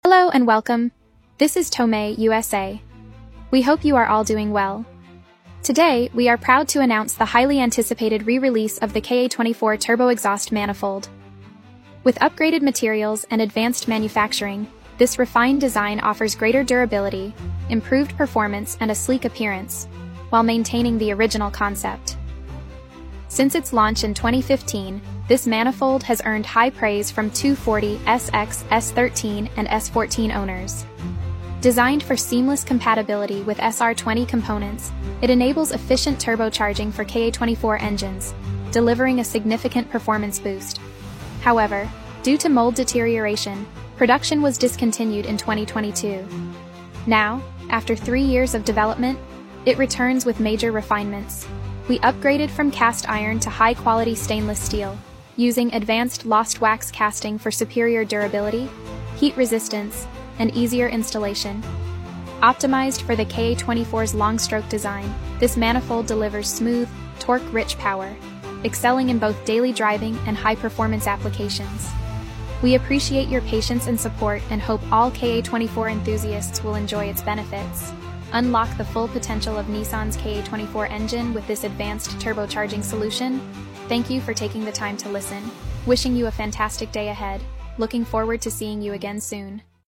The KA24 Turbo Exhaust Manifold sound effects free download